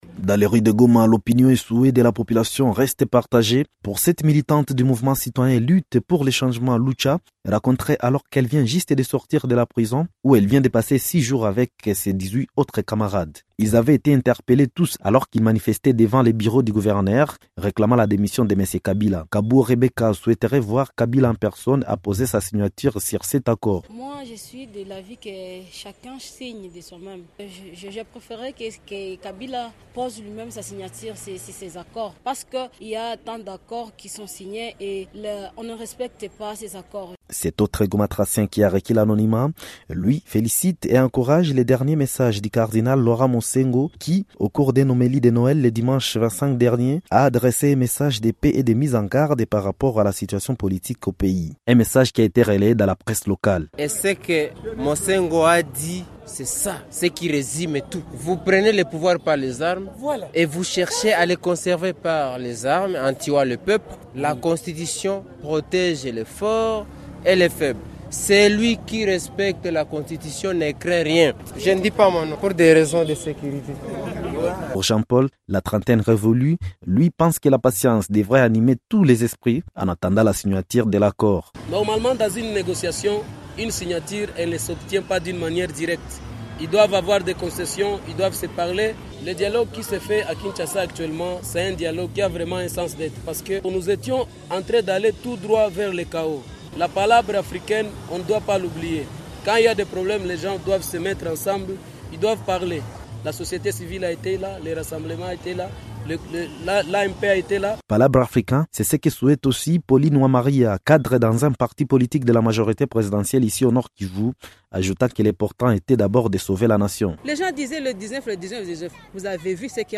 Goma la population donne son point de vue sur ce dialogue :
Reportage